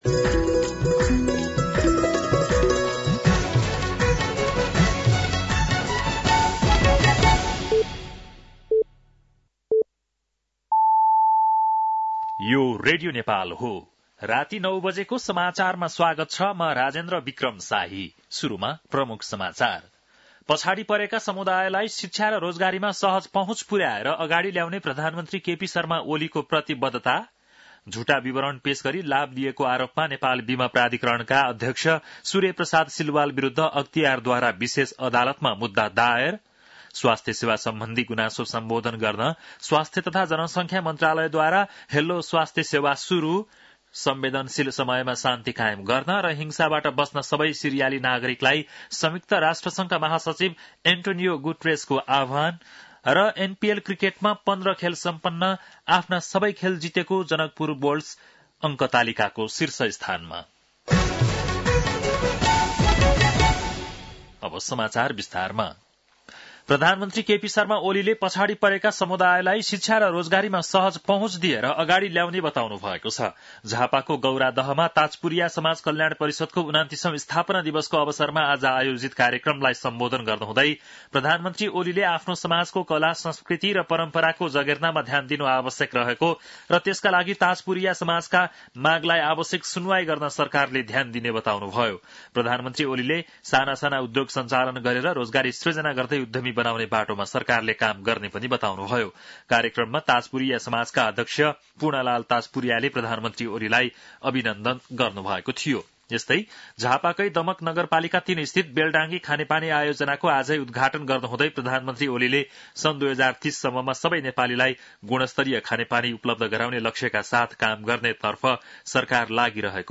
बेलुकी ९ बजेको नेपाली समाचार : २५ मंसिर , २०८१